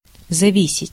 Ääntäminen
Ääntäminen France: IPA: /de.pɑ̃dʁ/ Haettu sana löytyi näillä lähdekielillä: ranska Käännös Ääninäyte 1. зависеть {n} (zaviset) Määritelmät Verbit Détacher une chose de l’ endroit où elle était pendue .